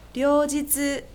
a 両日（りょうじつ）＝　Both days
• Reading: The on’yomi readings of the kanji.
6-ryoujitsu.mp3